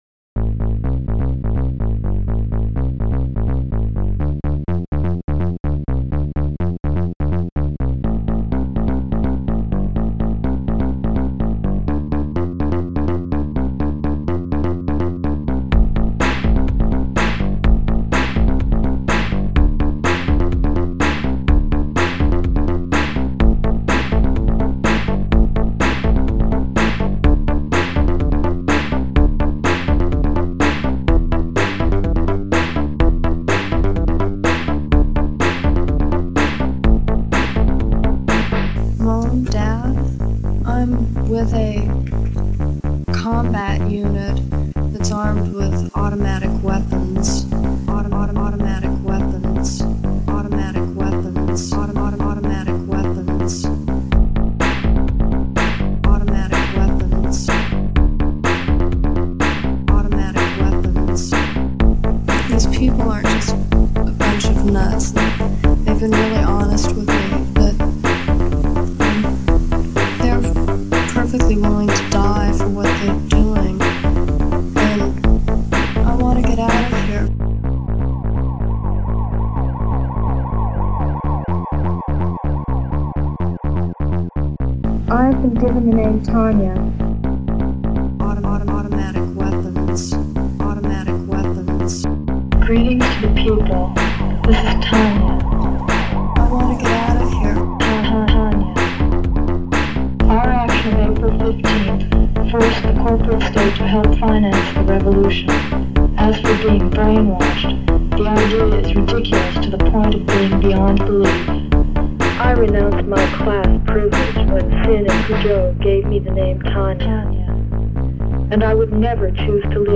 Una banda de pop angst alternativa